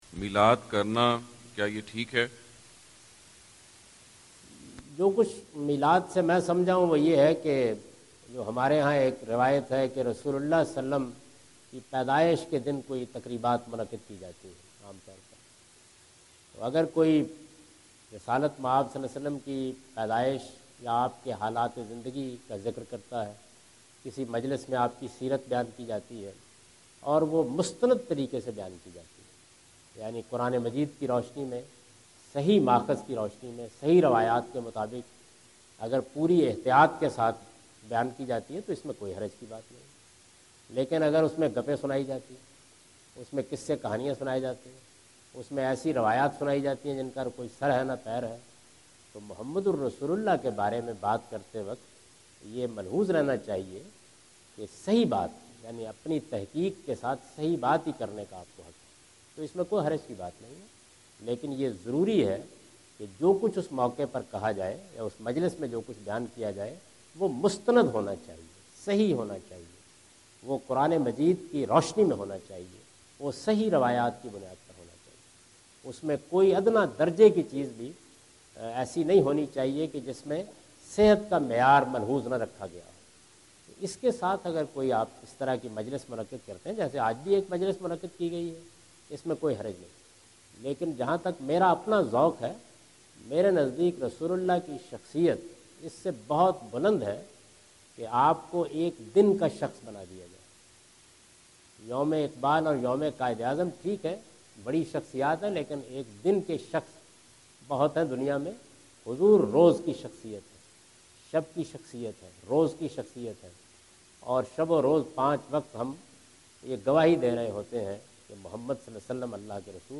Javed Ahmad Ghamidi answer the question about "The Most Celebrated Personality" During his US visit in New Jersy.
جاوید احمد غامدی اپنے دورہ امریکہ 2015 کے دوران نیو جرسی میں "حضرت محمد رسول اللہ ﷺ کی شان بیان کرنا" سے متعلق ایک سوال کا جواب دے رہے ہیں۔